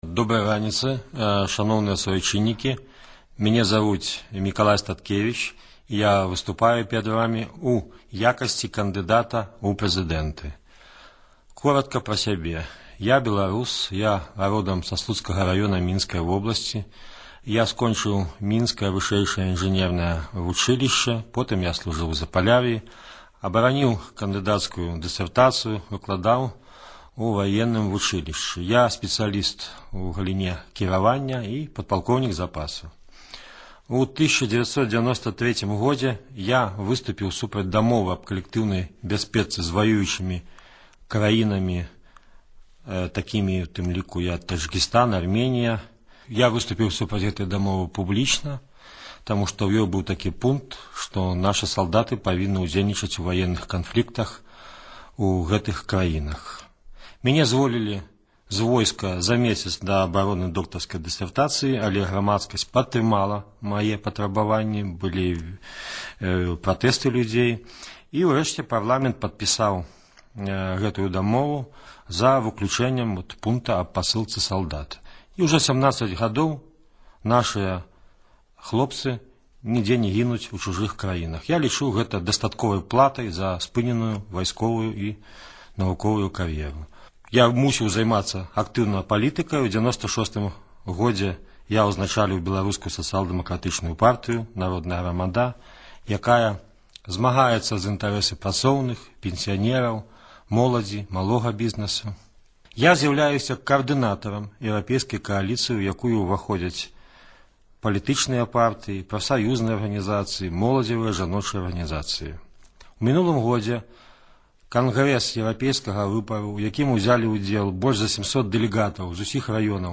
Радыёвыступ Мікалая Статкевіча